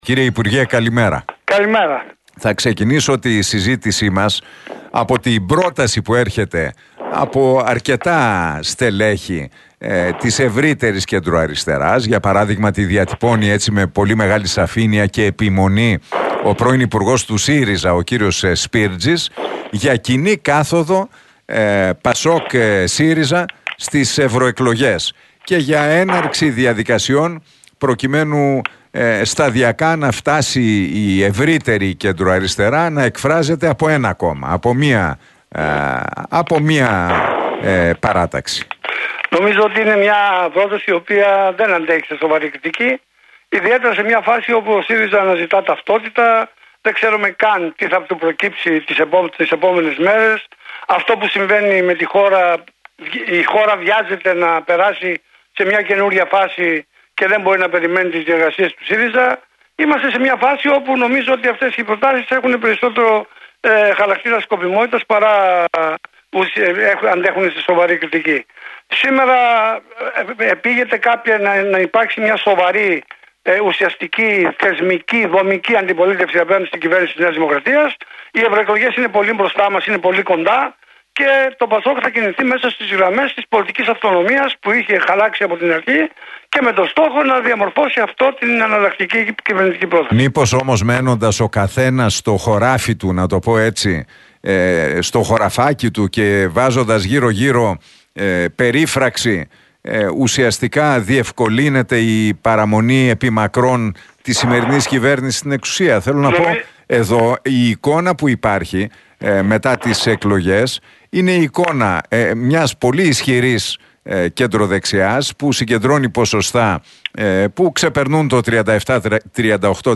«Ο ΣΥΡΙΖΑ αναζητά ταυτότητα, δεν ξέρουμε καν τι θα του προκύψει τις επόμενες ημέρες. Η χώρα βιάζεται να περάσει σε μια επόμενη φάση και δεν μπορεί να περιμένει τις διεργασίες του ΣΥΡΙΖΑ» δήλωσε το μέλος του Πολιτικού Συμβουλίου ΠΑΣΟΚ ΚΙΝΑΛ και πρώην υπουργός, Κώστας Σκανδαλίδης, στην εκπομπή του Νίκου Χατζηνικολάου στον Realfm 97,8.